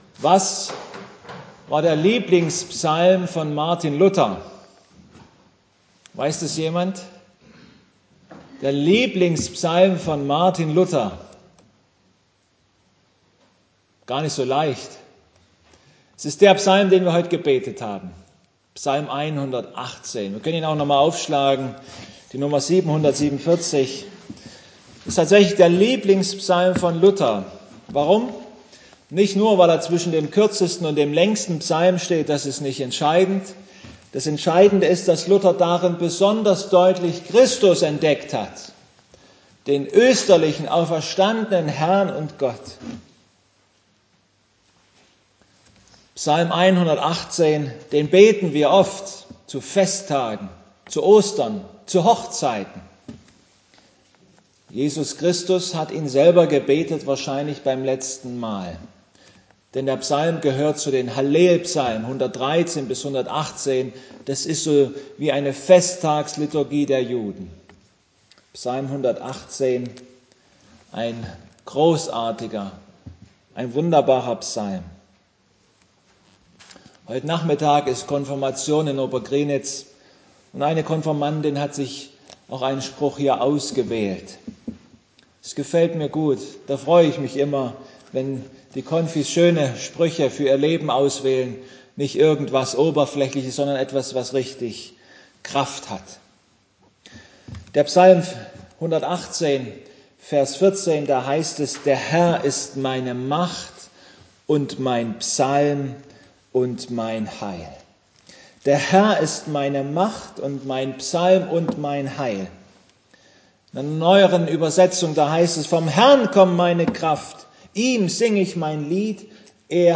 Passage: Ps 118, Sprüche Gottesdienstart: Abendmahlsgottesdienst http